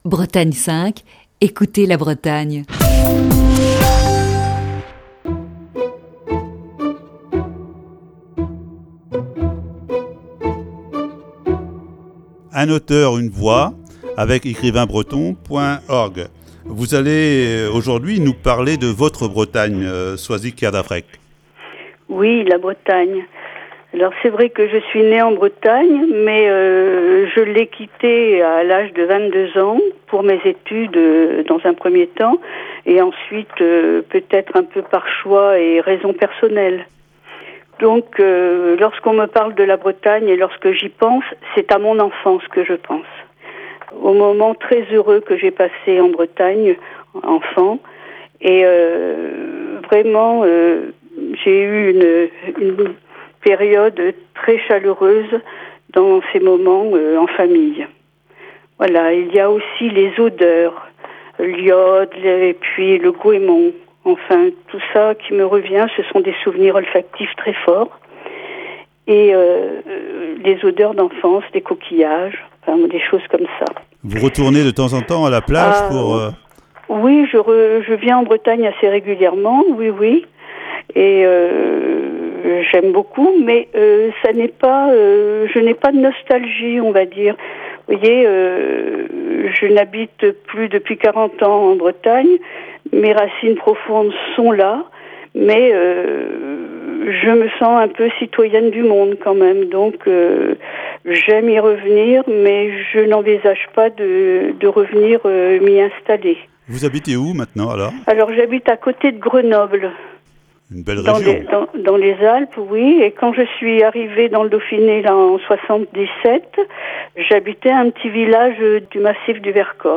Chronique du 2 juin 2020.
Ce matin, deuxième partie de cet entretien.